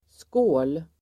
Uttal: [skå:l]